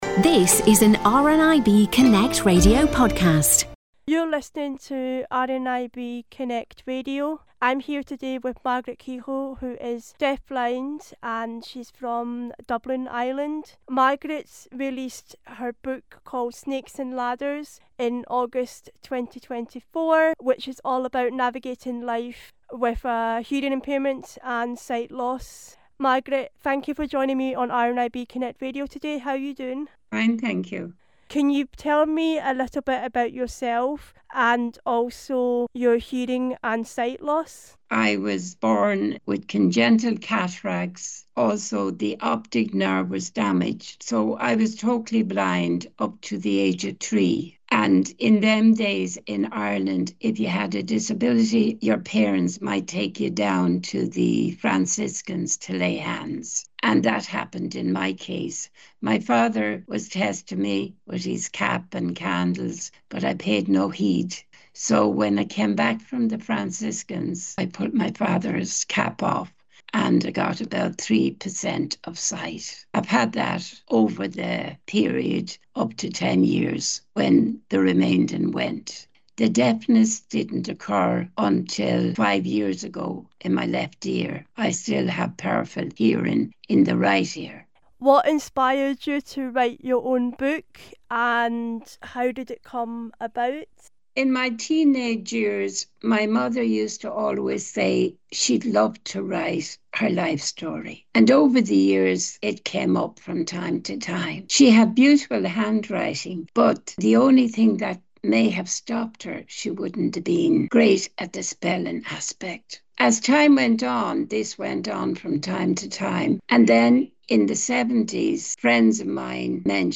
Book interview